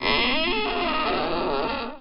door_creaks.wav